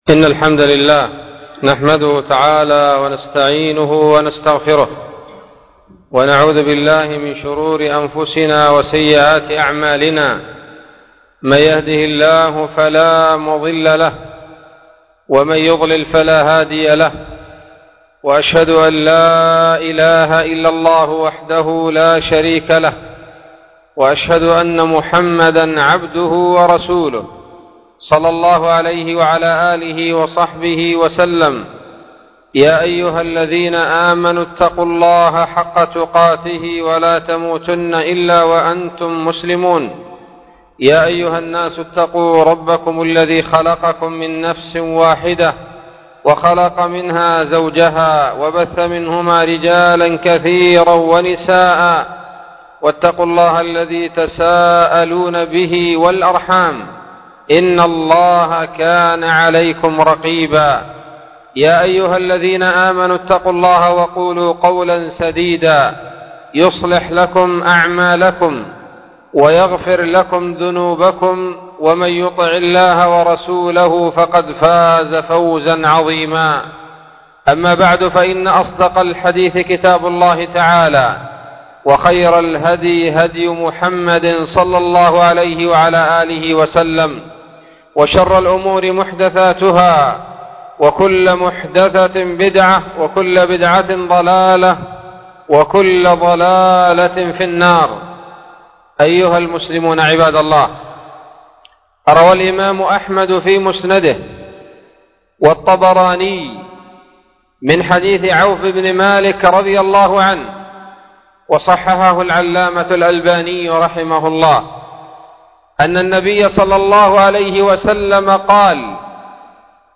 خطبة بعنوان : ((حرمة دم المسلم))